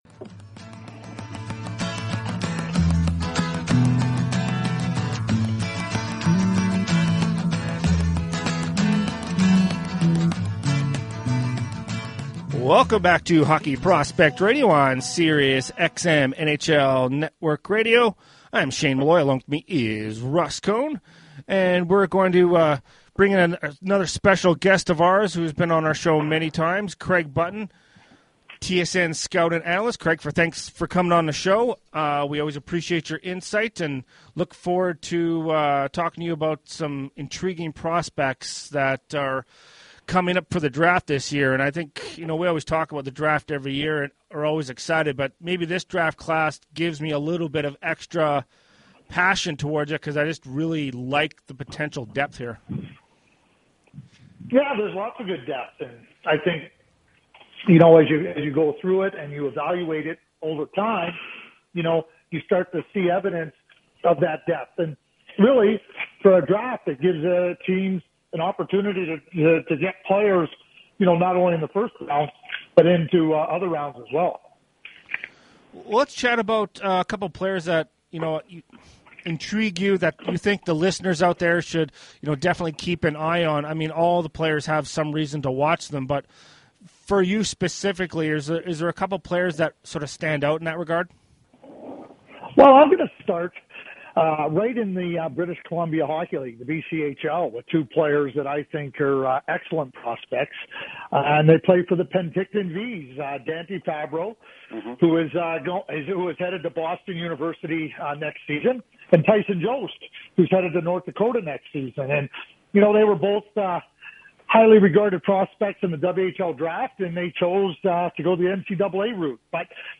Craig spoke about a few prospects including Dante Fabbro and Tyson Jost. The guys also discussed projecting players from multiple different leagues.